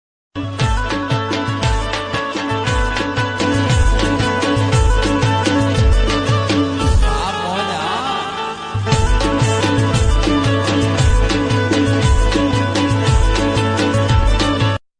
A beautiful flute tone inspired by Gujarati folk music.